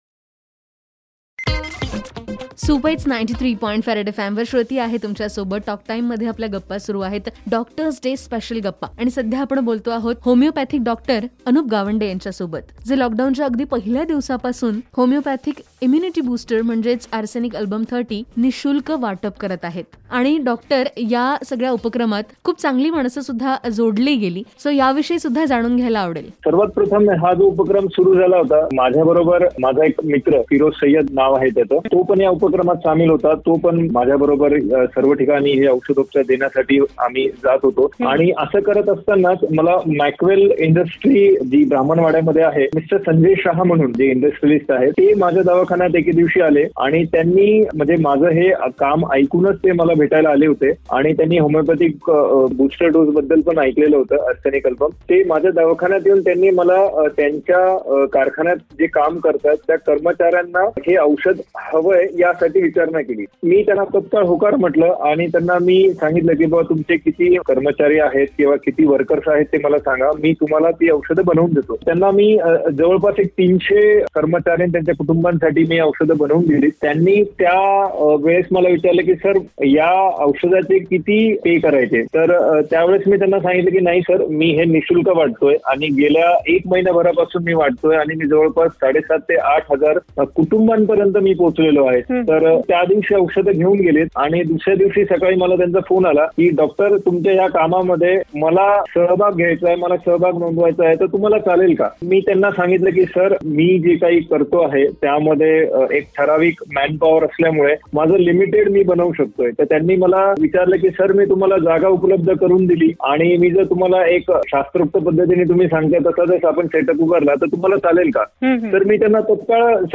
DOCTORS DAY SPECIAL INTERVIEW PART 2